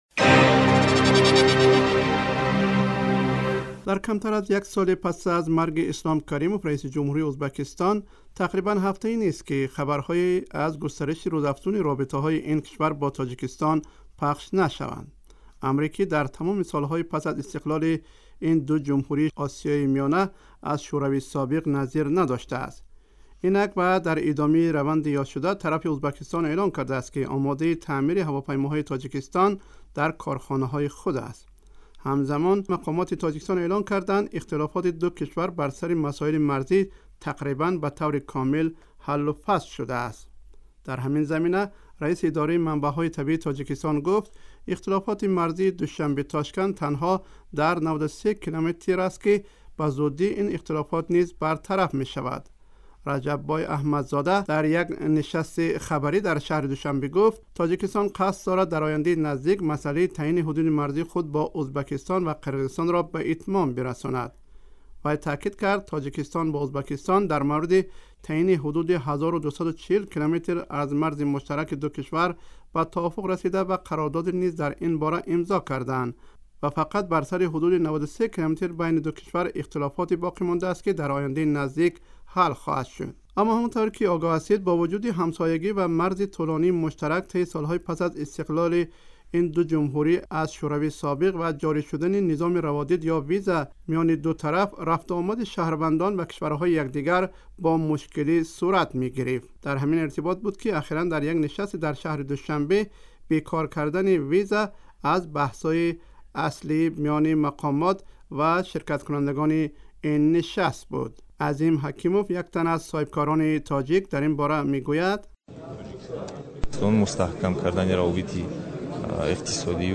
дар гузорише ба равобити ин ду кишвари ҳамсоя ва заминаҳои аз иртиқои он пардохтааст